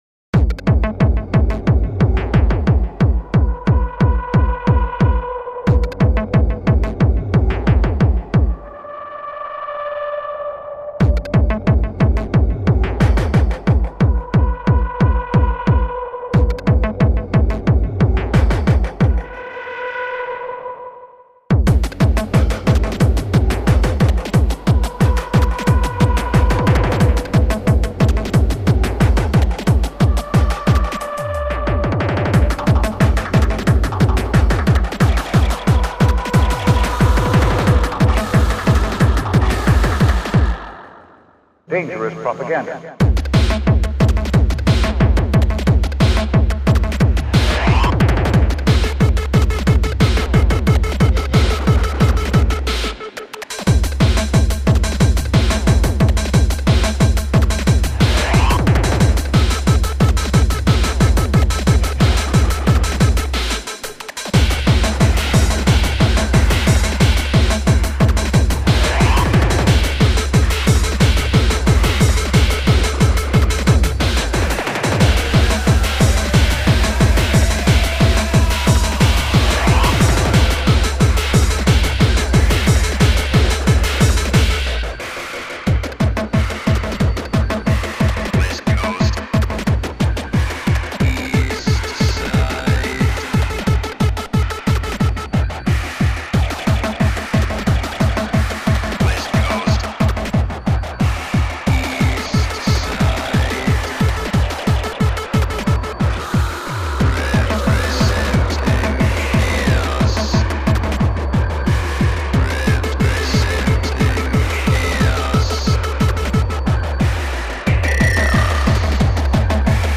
TEKNO TRIBETEK